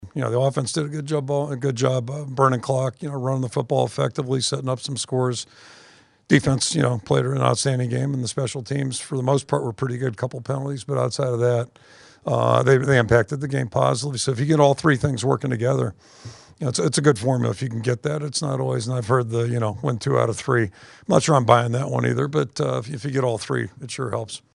That’s Iowa coach Kirk Ferentz.